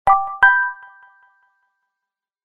Charming bell notification tone ringtone free download
Message Tones